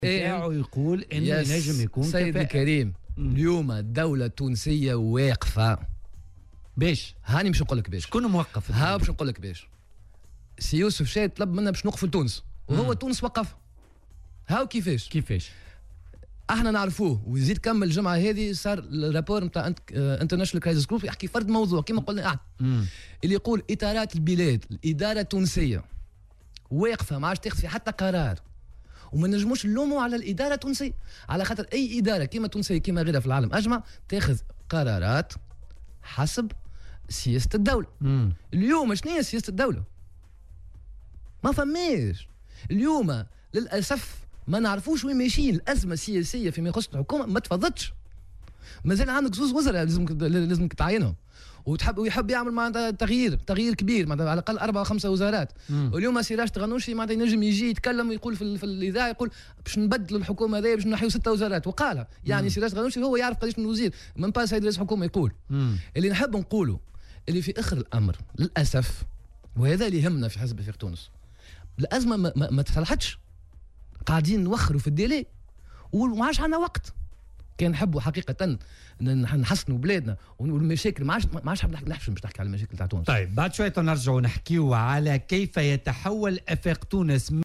وقال ضيف "بوليتيكا" على "الجوهرة اف أم" وبالدارجة التونسية " الدولة واقفة وسي يوسف الشاهد طلب منا الوقوف لتونس وهو تونس وقّفها".